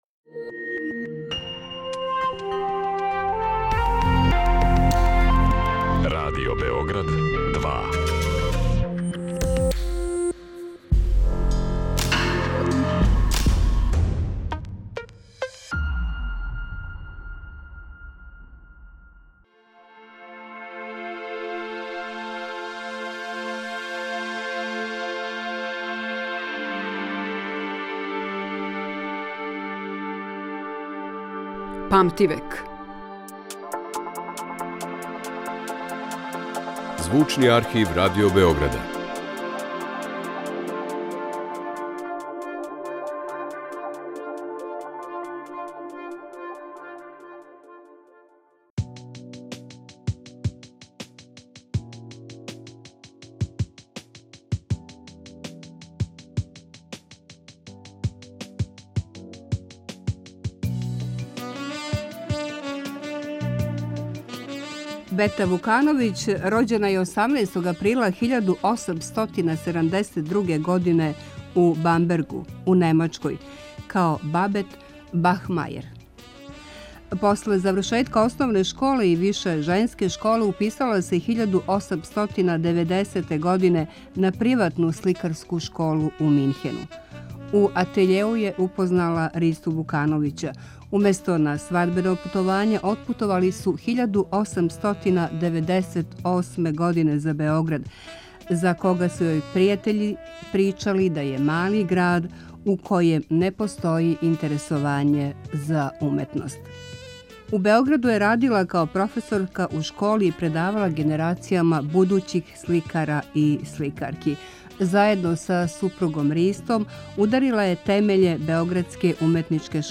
Стварала је у прелазном периоду између реализма и импресионизма. У данашњој емисији слушамо тонске записе из 1972. године.